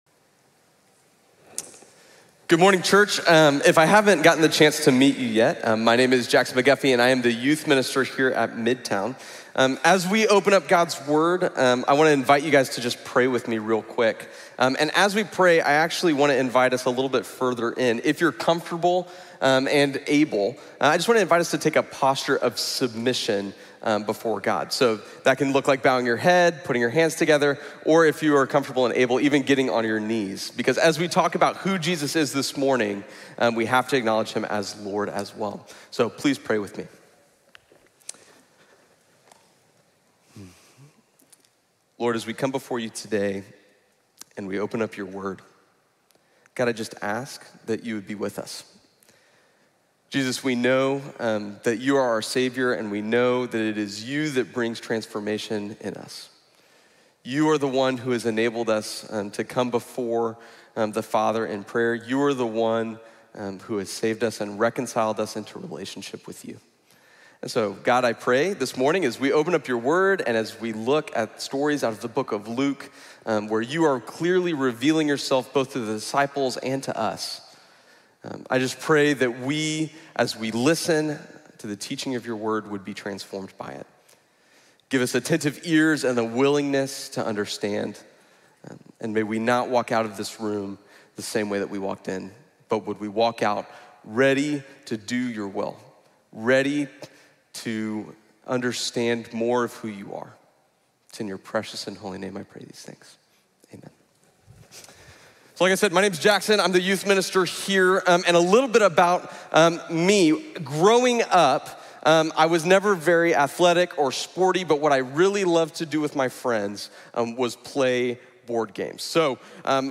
| Sermon | Grace Bible Church